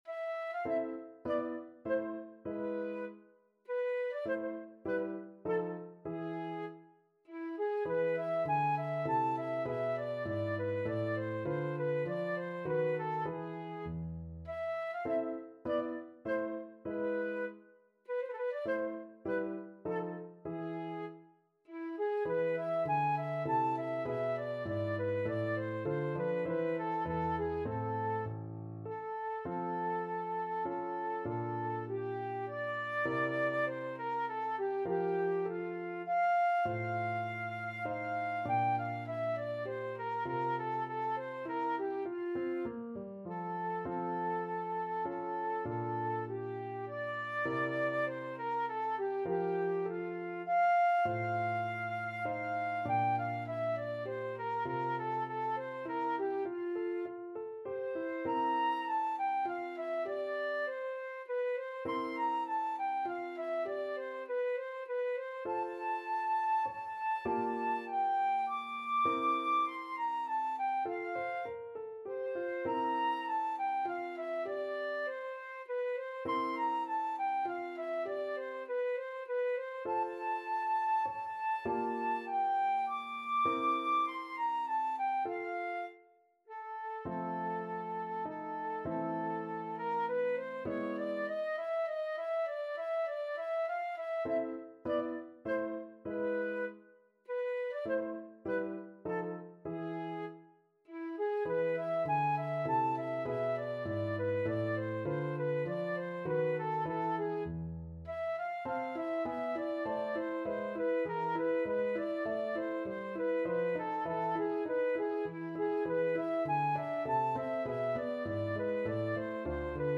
Classical Beethoven, Ludwig van 11 Bagatelles Op.119 No.1 Flute version
Flute
A minor (Sounding Pitch) (View more A minor Music for Flute )
Allegretto
3/4 (View more 3/4 Music)
Classical (View more Classical Flute Music)